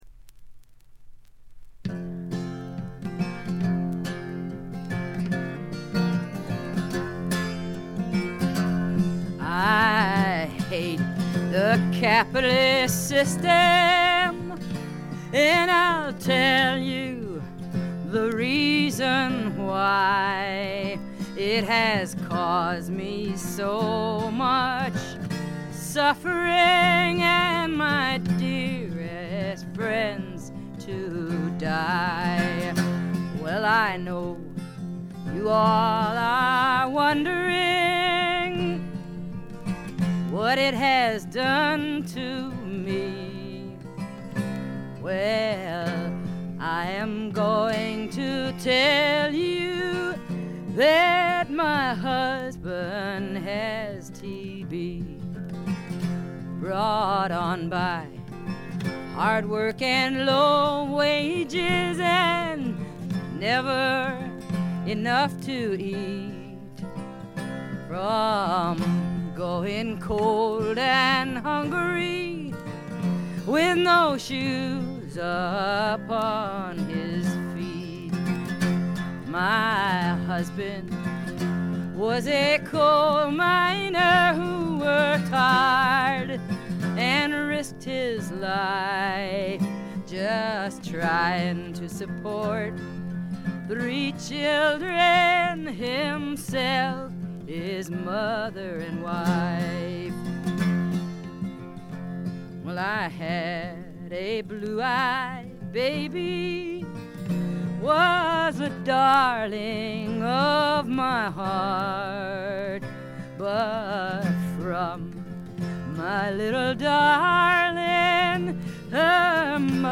チリプチ少々。散発的なプツ音2-3ヶ所。
存在感抜群の強靭なアルト・ヴォイスが彼女の最大の武器でしょう。
試聴曲は現品からの取り込み音源です。
Vocals, Guitar